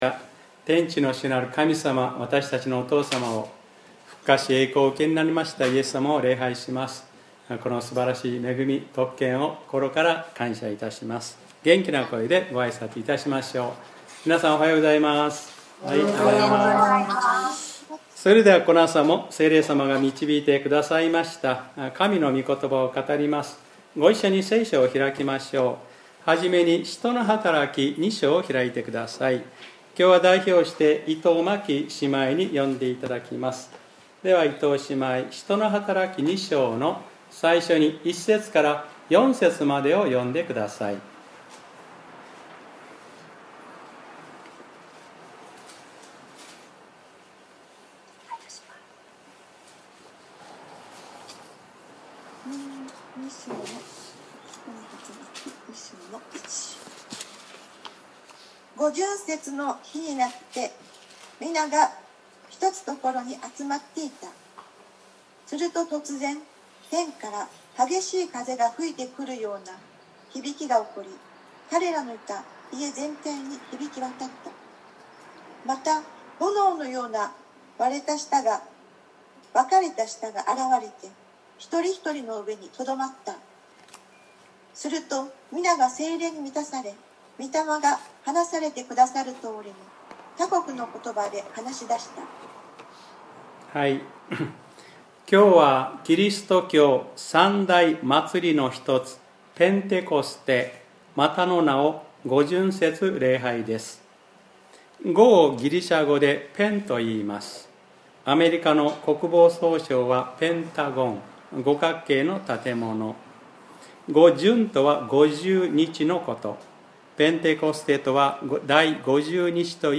2020年05月31日（日）礼拝説教『聖霊の交わり(コイノニア)』